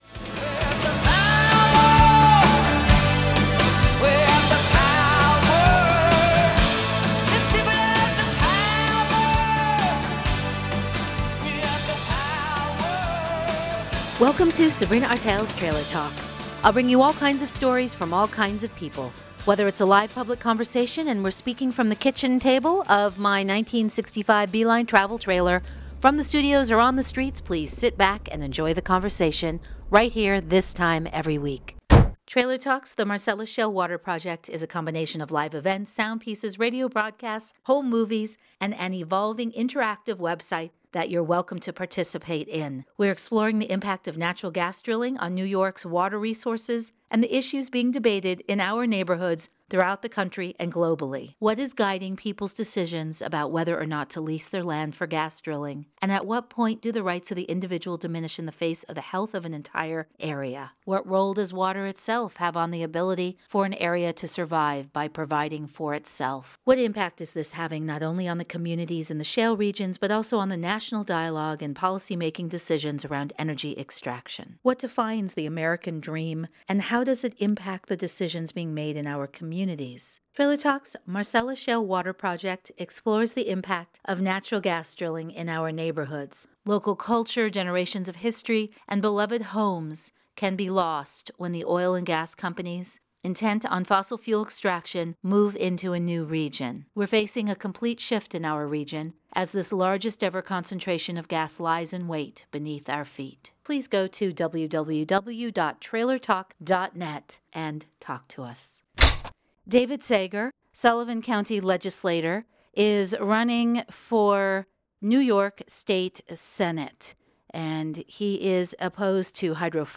I speak with David Sager, Sullivan County Legislator at a fundraiser for his Senate Campaign in Bethel, NY. The threat of natural gas drilling coming into his county (Sullivan) and the devastation that loosely regulated natural gas drilling will cause is the reason he decided to enter the race.